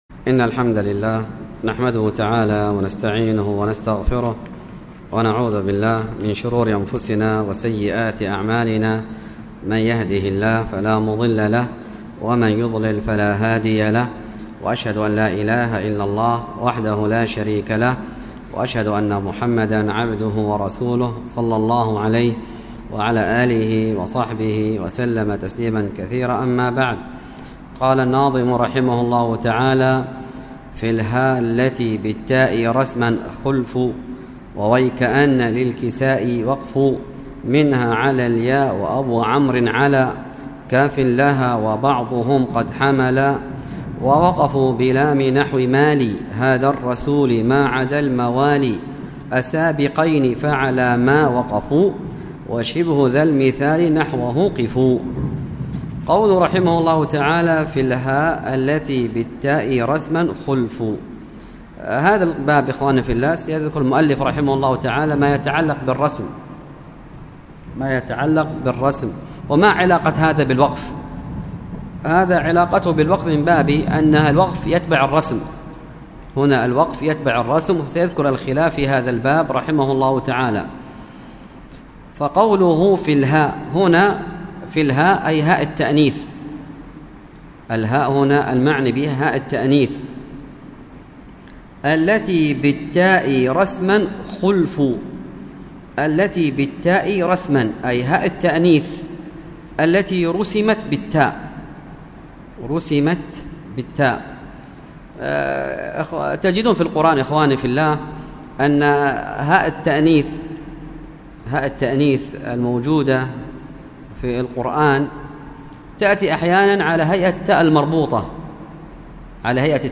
خطبة مفرغة ألقيت بدار الحديث بالفيوش بتاريخ 28 رجب 1442هـ الموافق 12 مارس 2021م.